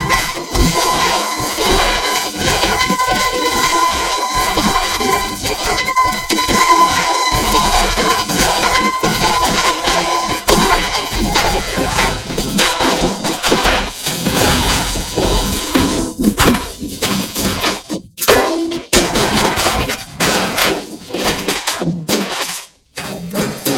musika-hyperpop